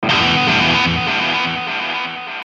描述：自带字符串
Tag: 100 bpm Rock Loops Guitar Electric Loops 413.52 KB wav Key : Unknown